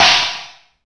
1 channel
Drums05C.mp3